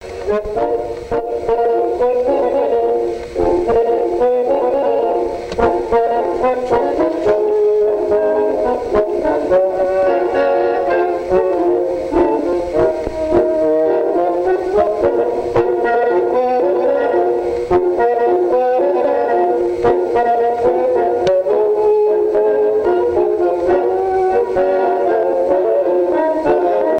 Mémoires et Patrimoines vivants - RaddO est une base de données d'archives iconographiques et sonores.
danse : tango
Pièce musicale inédite